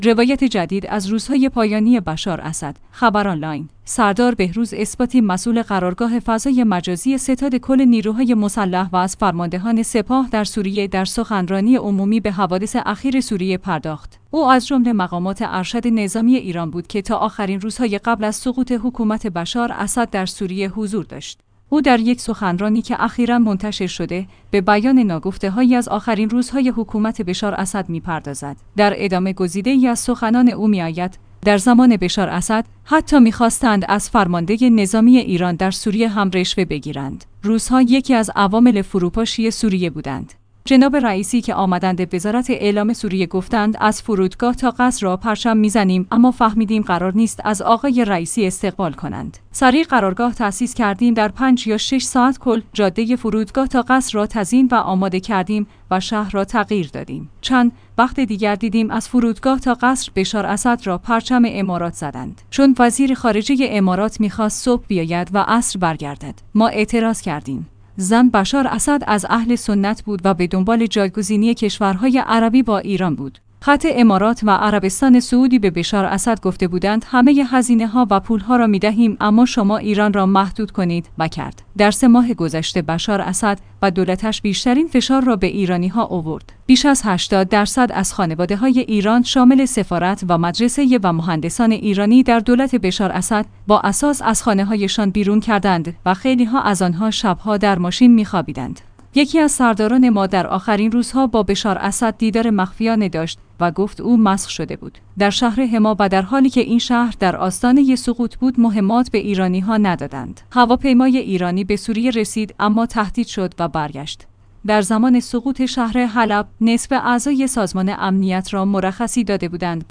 خبرآنلاین/ سردار بهروز اثباتی مسئول قرارگاه فضای مجازی ستاد کل نیروهای مسلح و از فرماندهان سپاه در سوریه در سخنرانی عمومی به حوادث اخیر سوریه پرداخت.